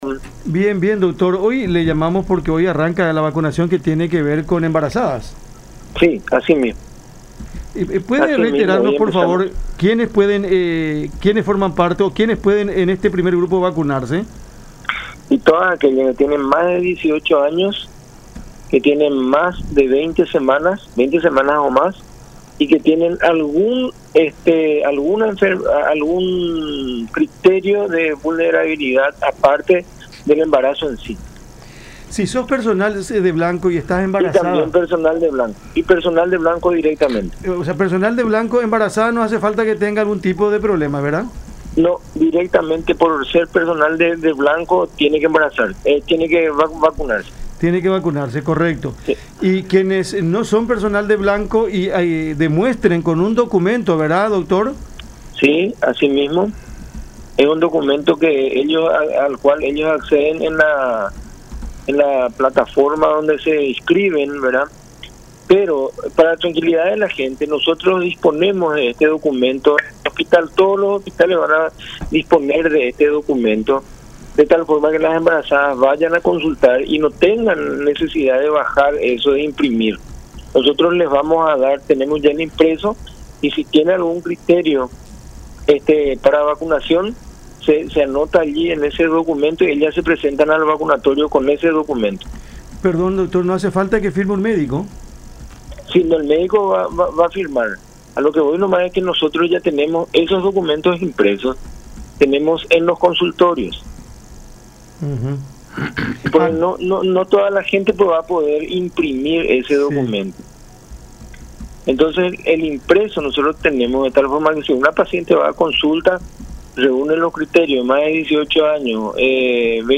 en conversación con Cada Mañana por La Unión